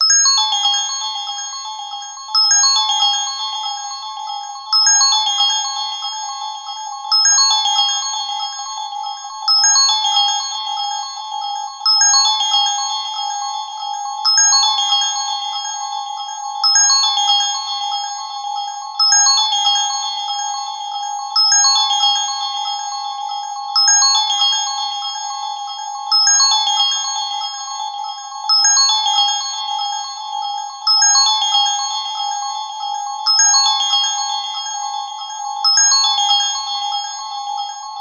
優しい音色のオルゴール着信音。